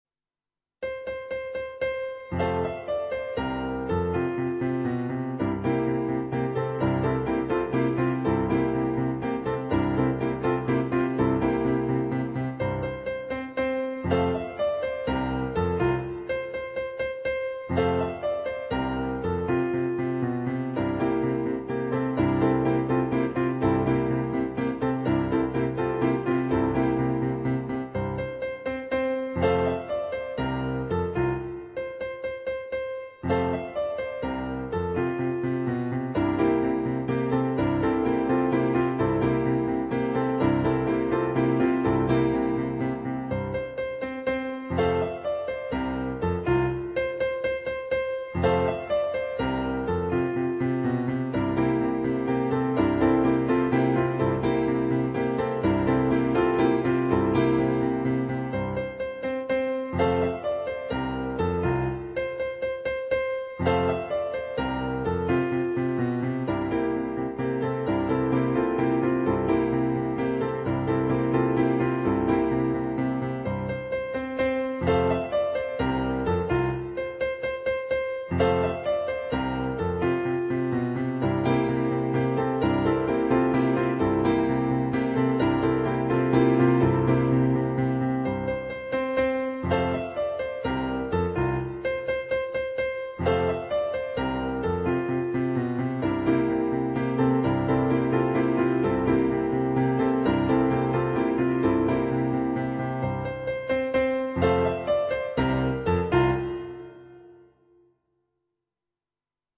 for Solo Piano
on Yamaha digital pianos.